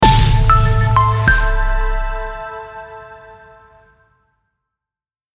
Download Breaking News sound effect for free.
Television Breaking News